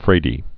(frādē)